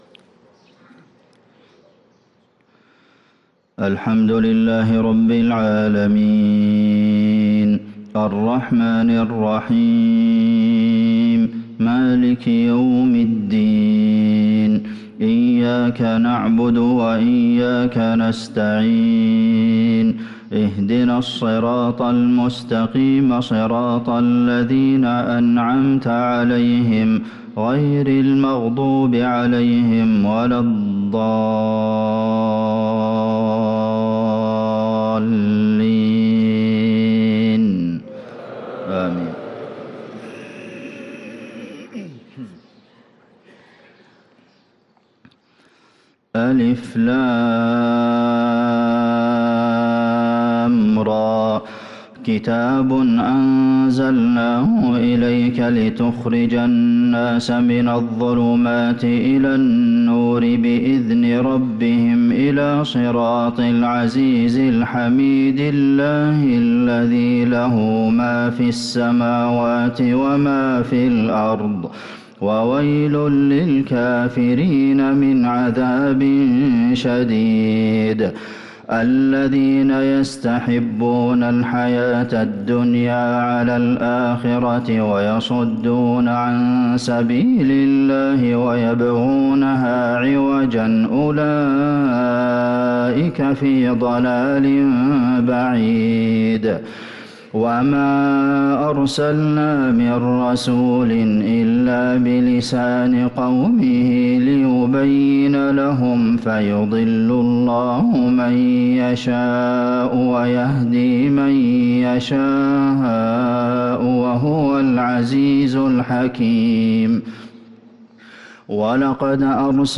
صلاة الفجر للقارئ عبدالمحسن القاسم 24 جمادي الأول 1445 هـ